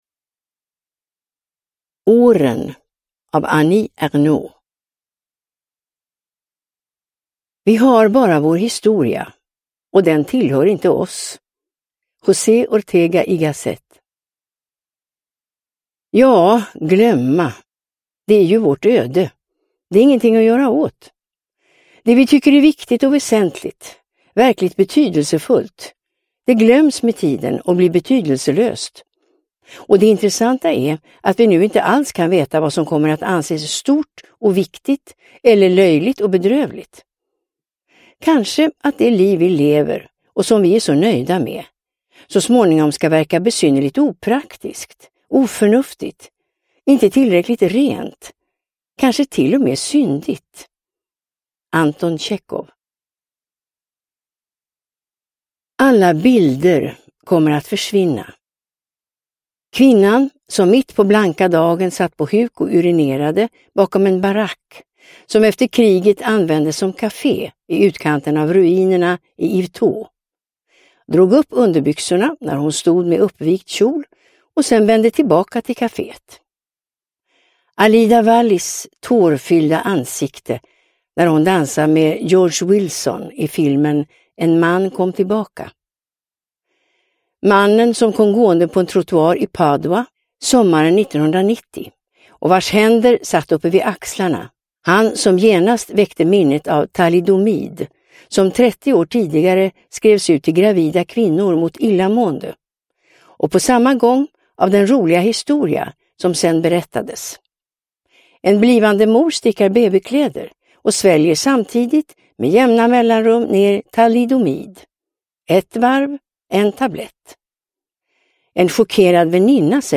Åren – Ljudbok – Laddas ner